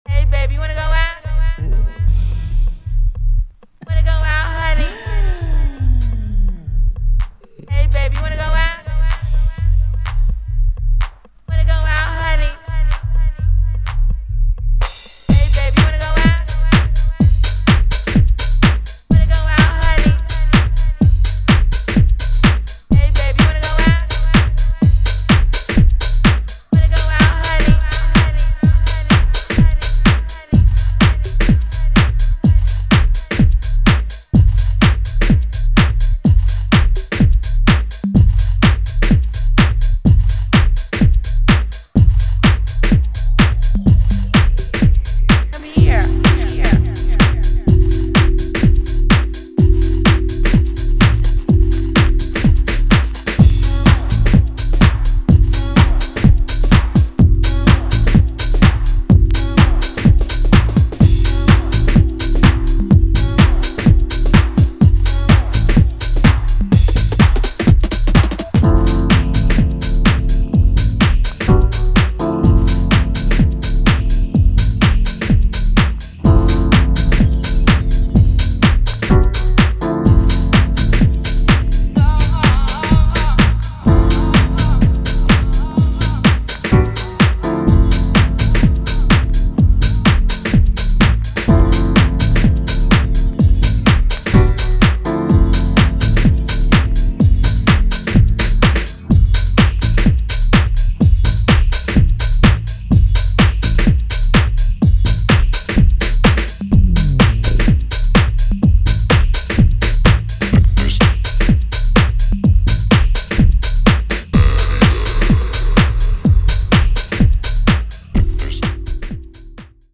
deep chords and bumpy house groove
Acid , House , Minimal